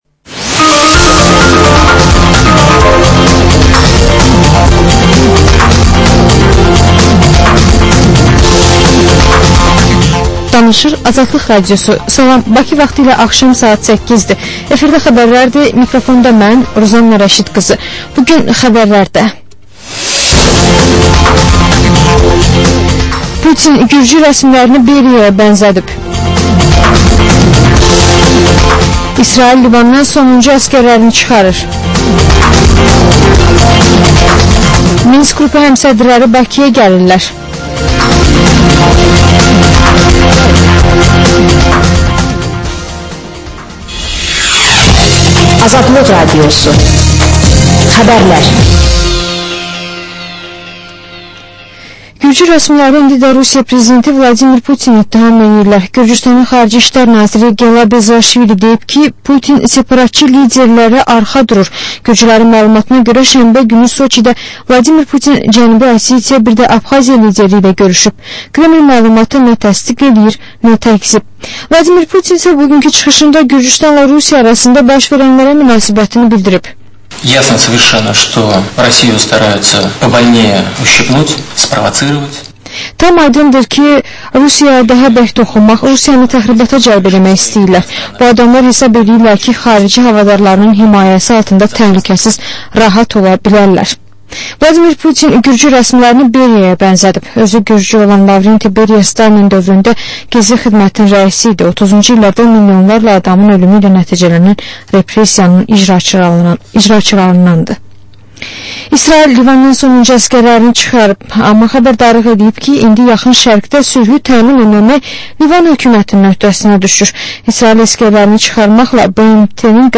Xəbərlər
Xəbərlər, reportajlar, müsahibələr.